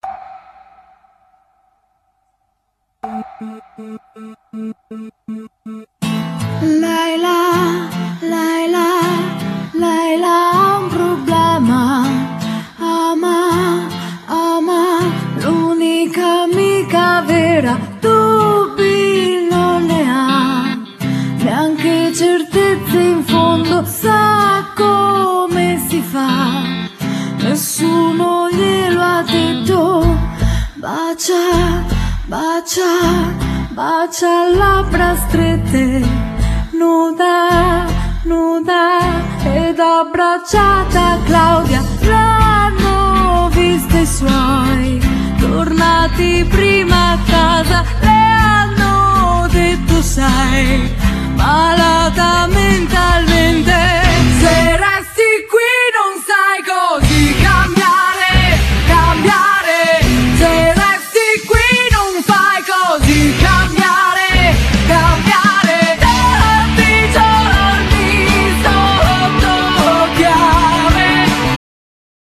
Genere : Metal